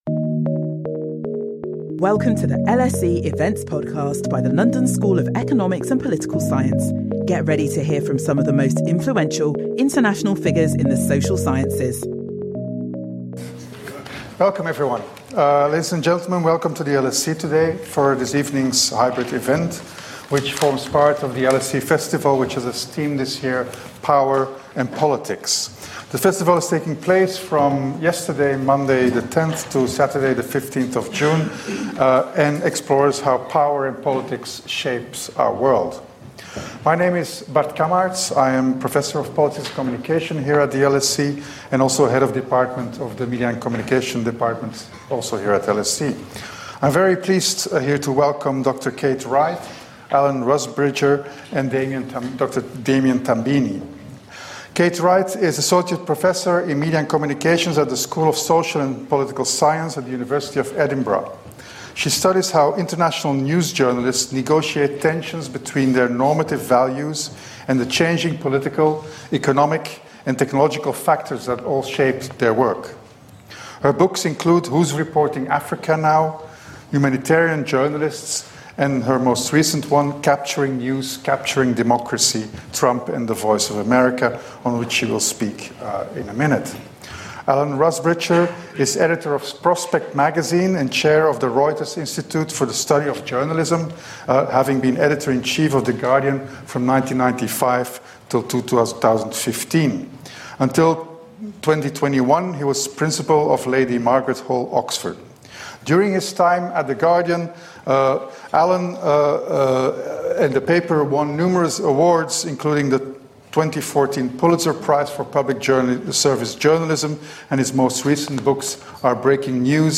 Our expert panel examine the disconcerting dynamic between authoritarian populism and public service media.